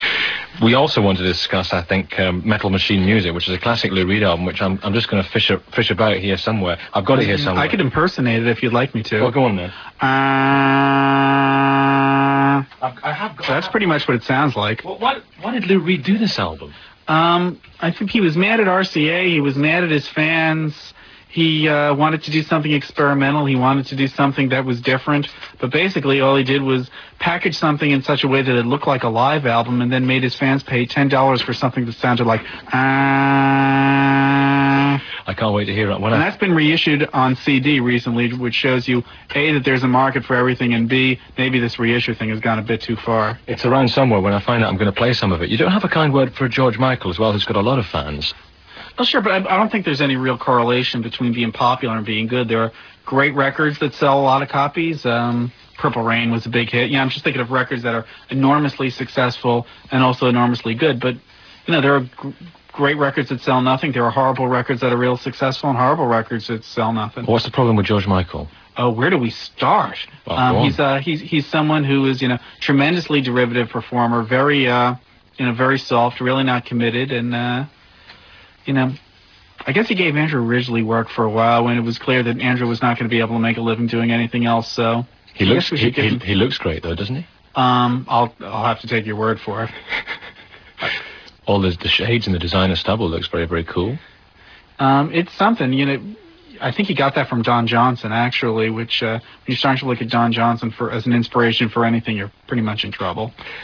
The interviews on this Site are all streaming Real Audio.